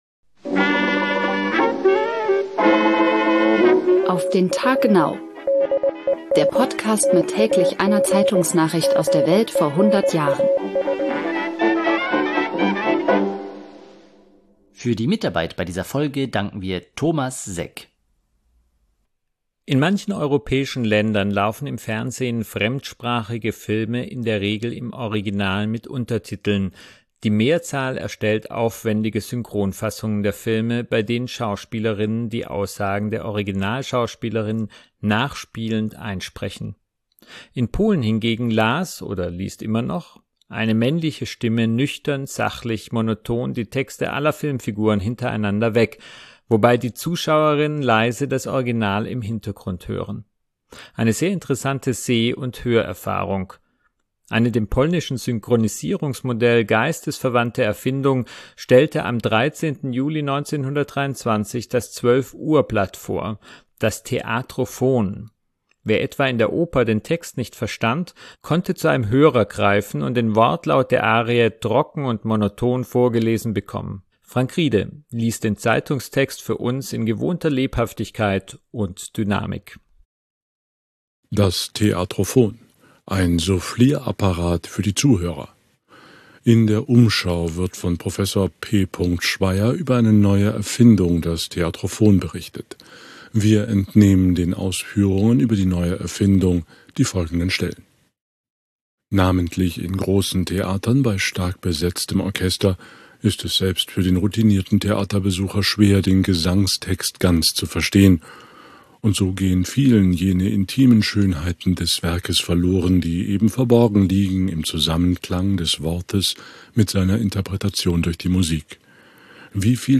liest den Zeitungstext für uns in gewohnter Lebhaftigkeit